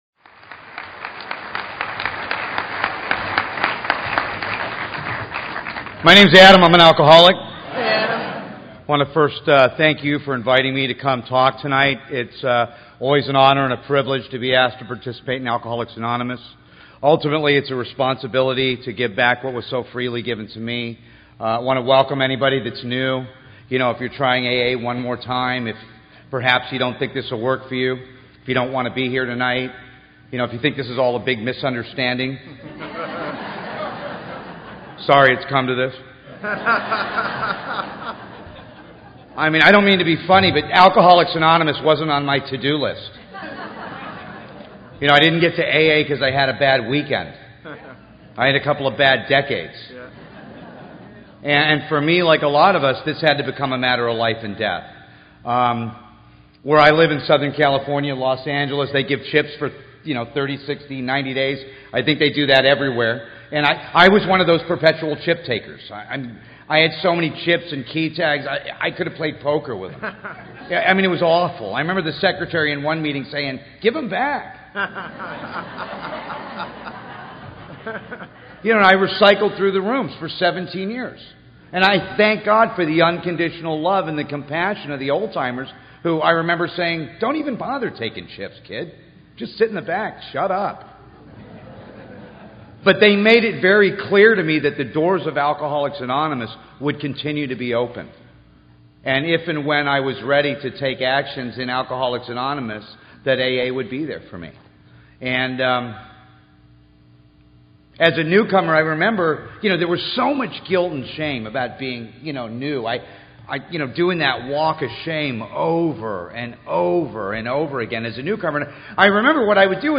AA Speaker - Hilarious Recovery Share